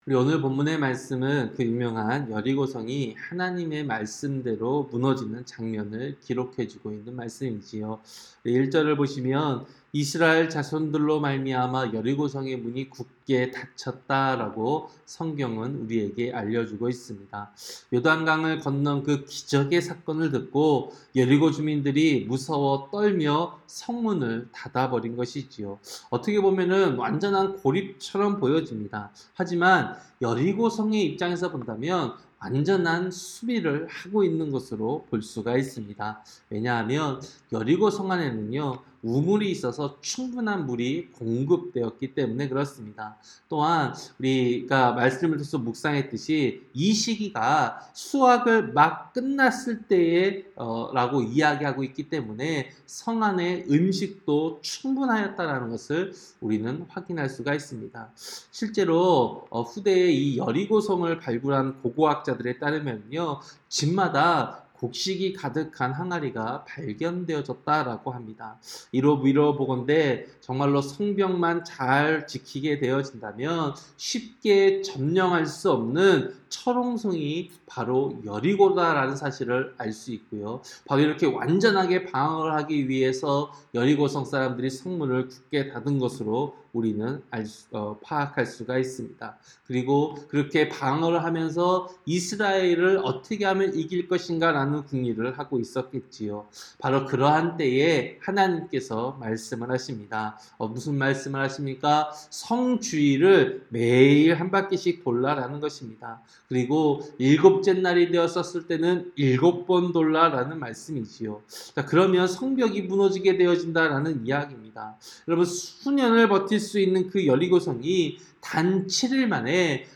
새벽설교-여호수아 6장